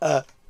yenburp_MFQU9lL.mp3